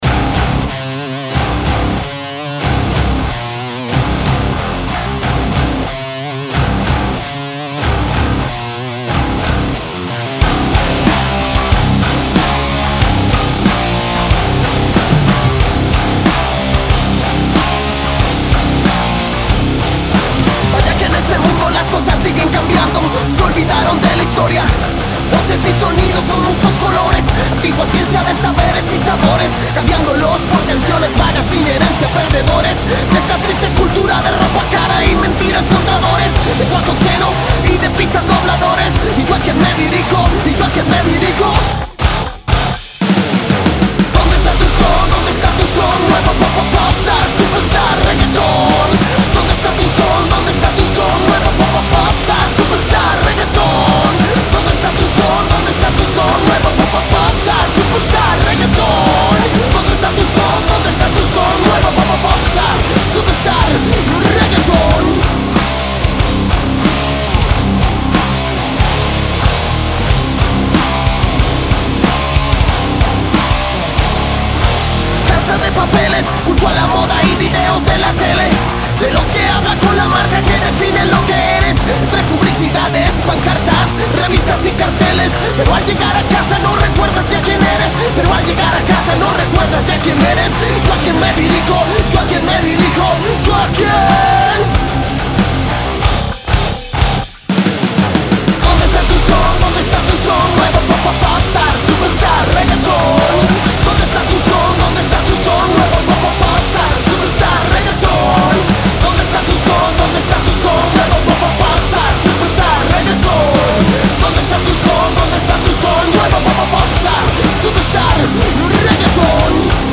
Rap metal